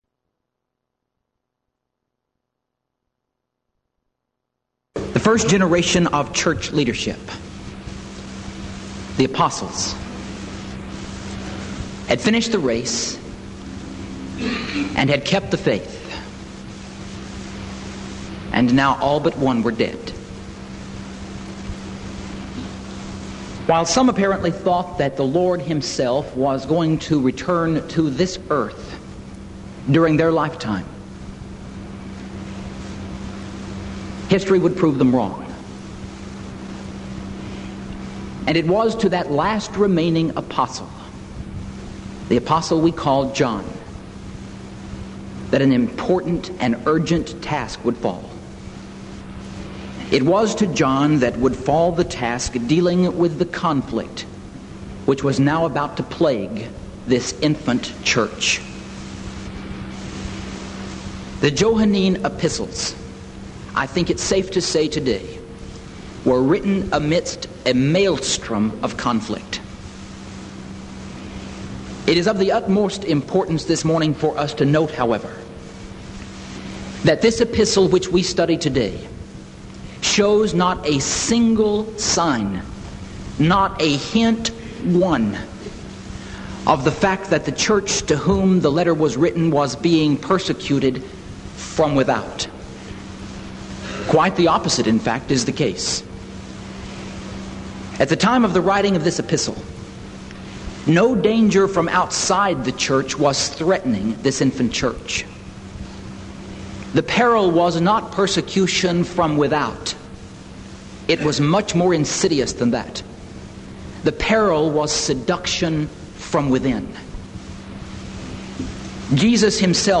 Event: 1987 Denton Lectures Theme/Title: Studies In I, II, III John